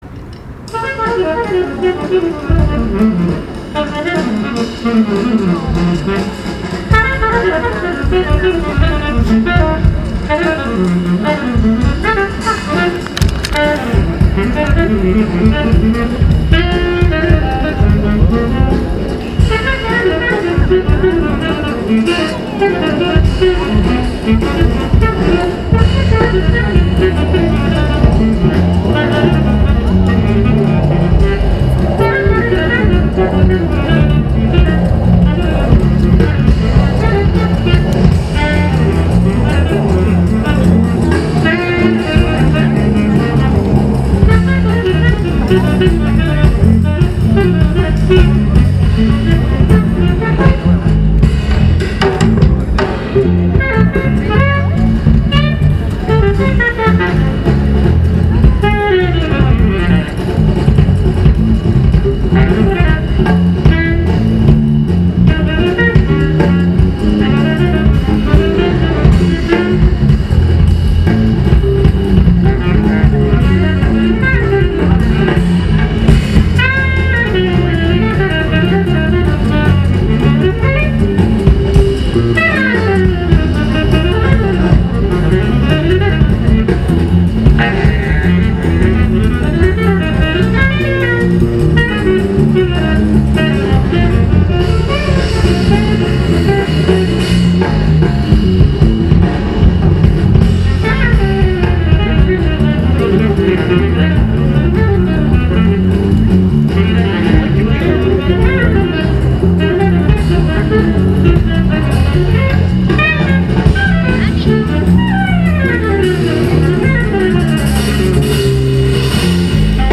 Noticias
...con un programa de jazz contemporáneo basado en composiciones escritas especialmente para la agrupación, y que pueden escucharse en su álbum Few minutes in the space.
En las áreas verdes del Cenart, de pie o en el pasto, los asistentes escucharon la música sincopada del trío, que interpretó piezas como Pardon, to tu; Poem For Franklin Rosemont, y Bach at night.